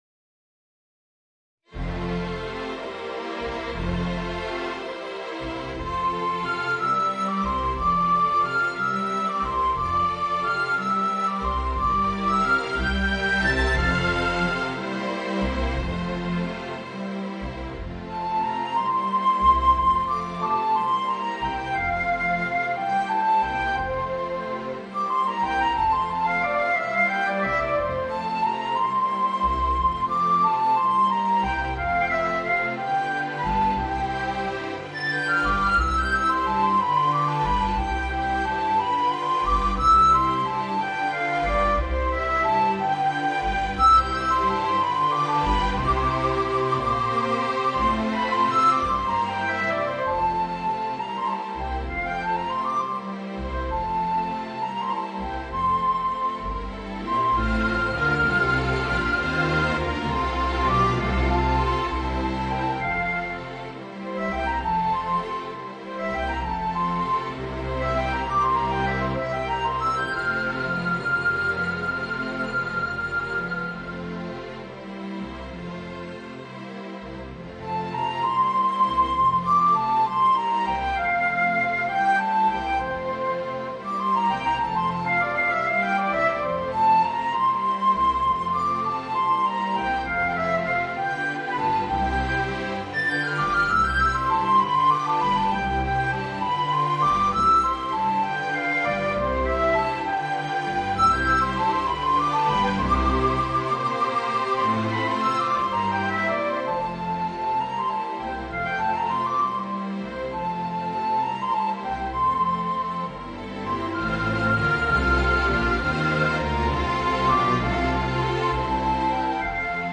Voicing: Trumpet and String Quintet